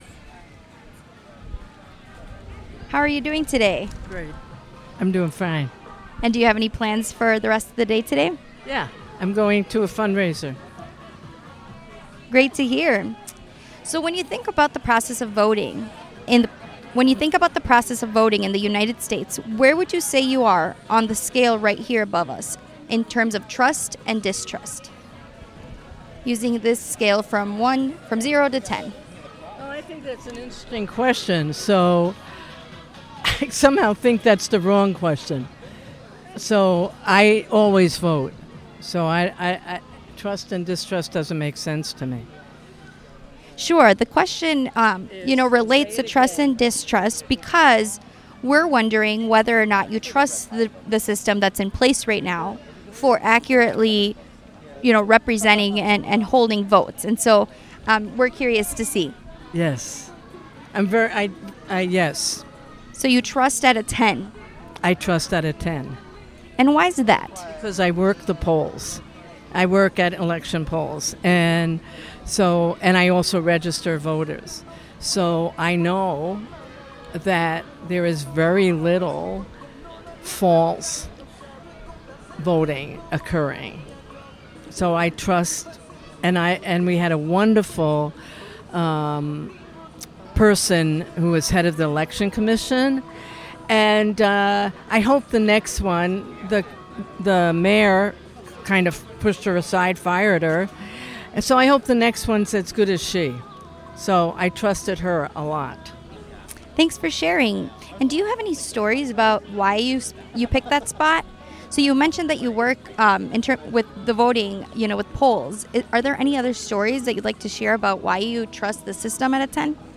Location Despensa de la Paz